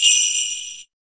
176UKPERC1-R.wav